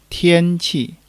tian1-qi4.mp3